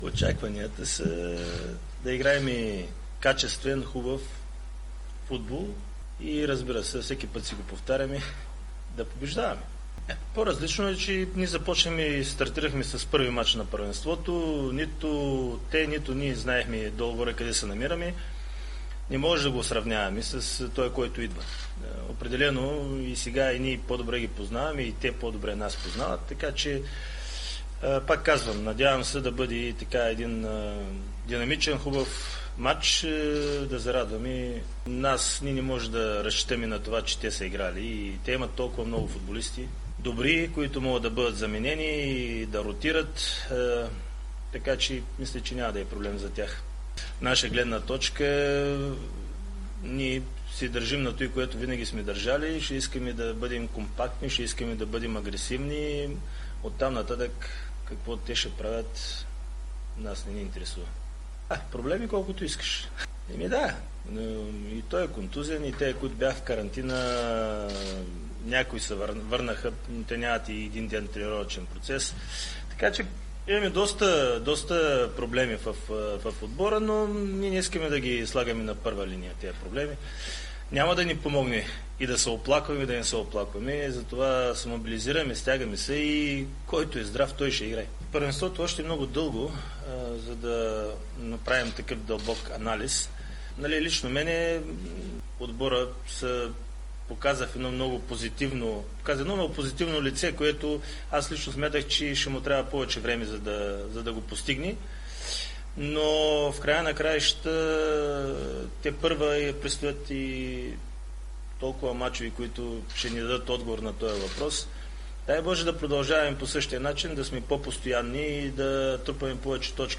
Главният мениджър на ЦСКА 1948 Красимир Балъков говори пред медиите преди двубоя с ЦСКА през уикенда.